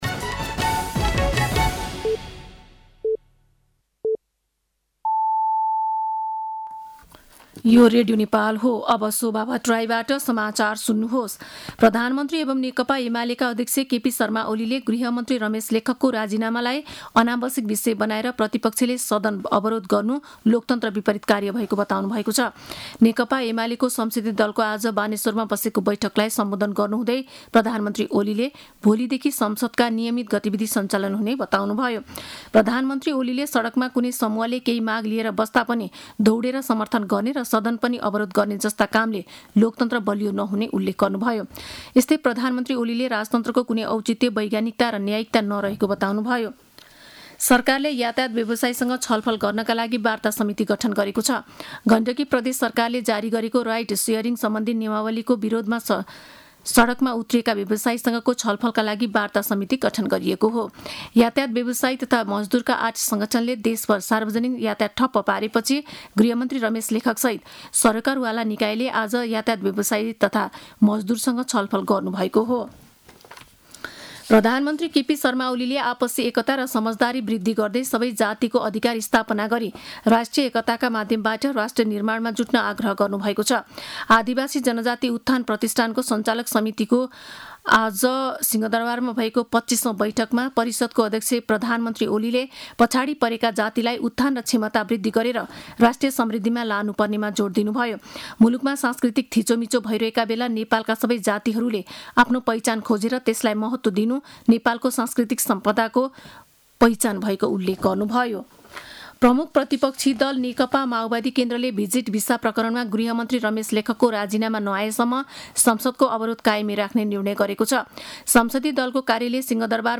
साँझ ५ बजेको नेपाली समाचार : १९ जेठ , २०८२
5.-pm-nepali-news.mp3